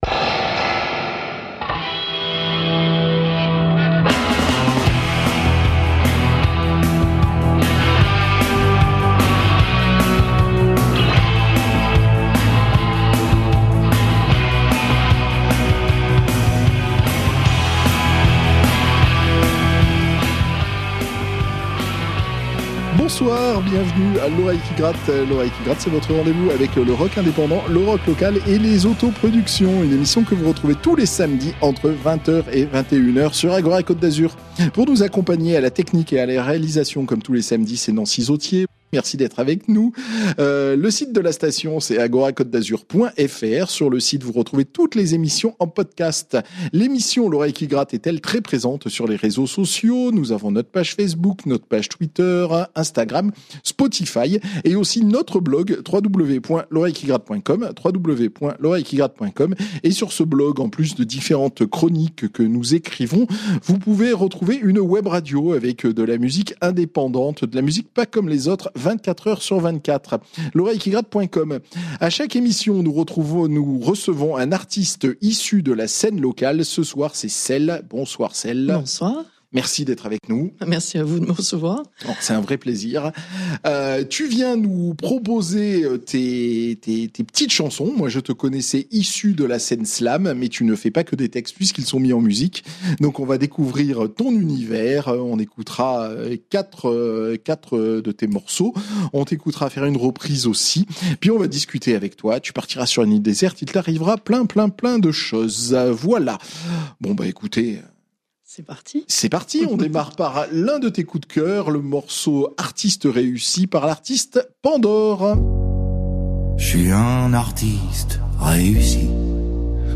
L'émission de la scène musicale régionale. Interview, playlist et live dans le studio de la radio.